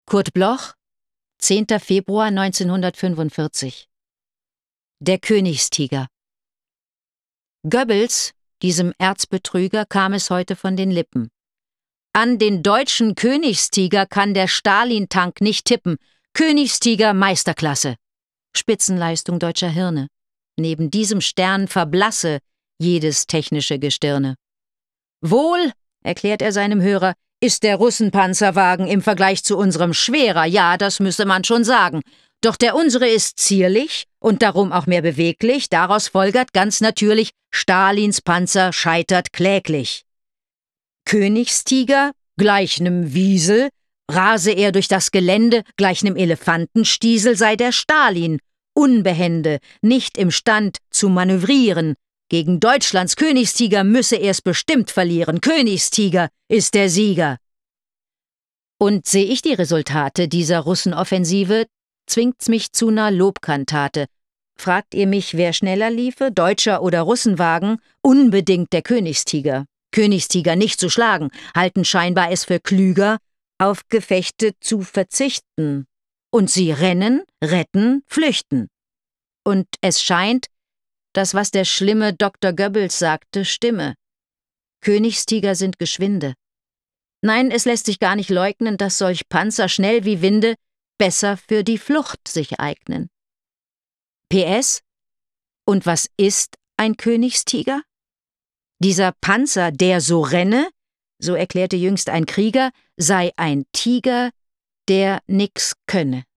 Recording: Splendid Synchron GmbH, Köln · Editing/Music: Kristen & Schmidt, Wiesbaden
Anke Engelke (* 1965) is a German actress who, already as a child, was a radio and TV presenter, thereby gaining journalistic experience at SWF3.